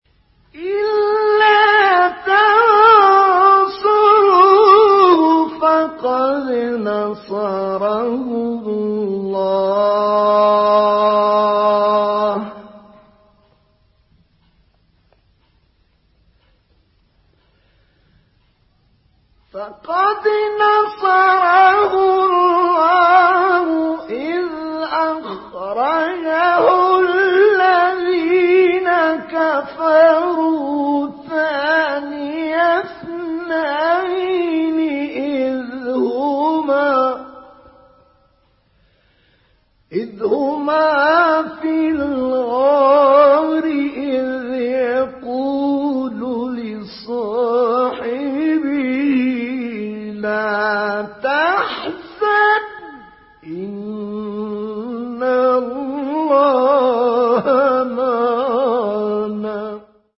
تلاوت سوره توبه آیه 40 با طه الفشنی | نغمات قرآن
سوره : توبه آیه: 40 استاد : طه الفشنی مقام : رست قبلی بعدی